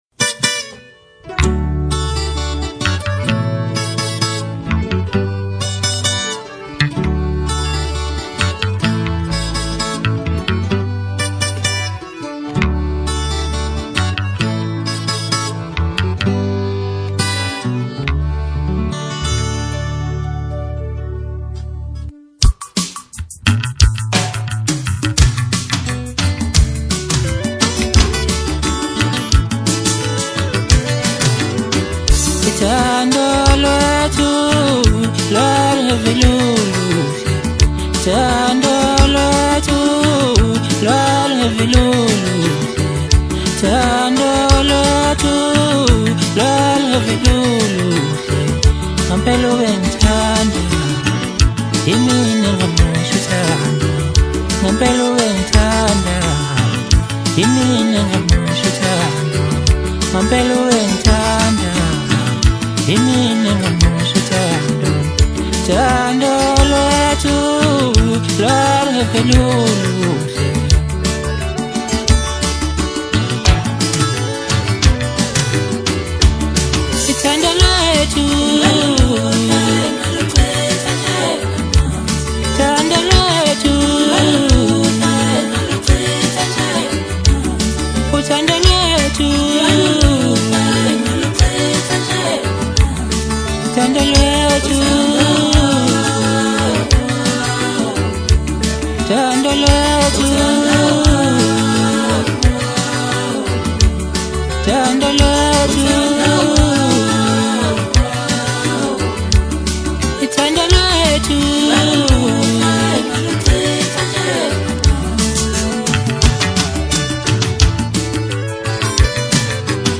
Home » DJ Mix » Hip Hop » Maskandi